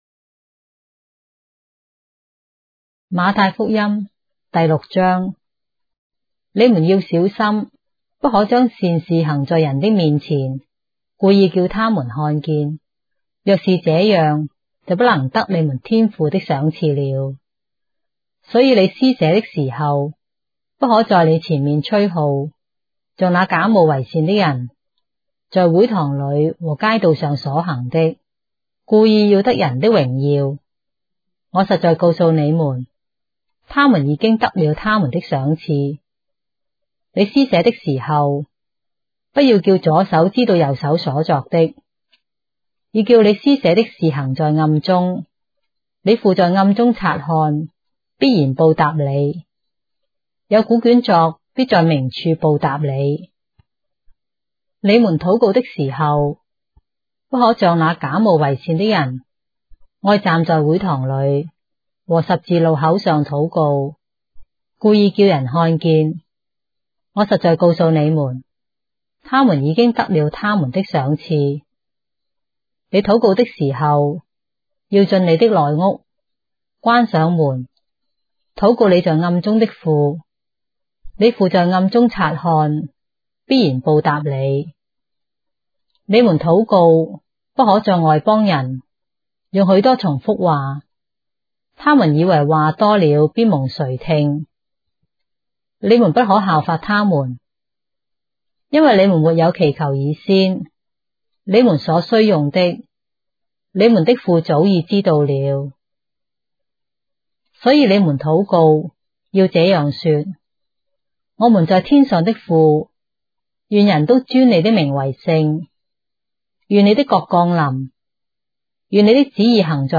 章的聖經在中國的語言，音頻旁白- Matthew, chapter 6 of the Holy Bible in Traditional Chinese